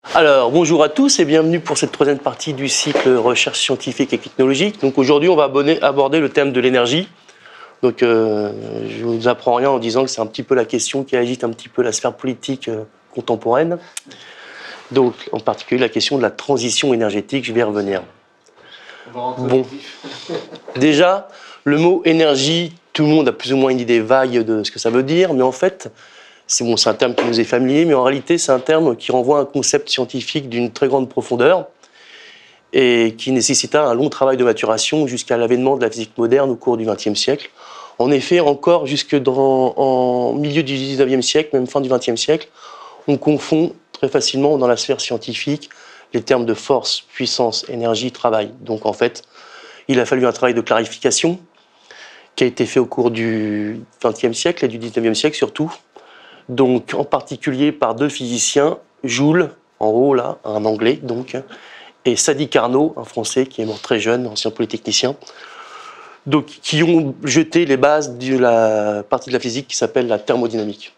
Dans ce séminaire, la délicate question de l'énergie est traitée à la fois sous l'angle de sa nature scientifique et de ses enjeux technico-économiques.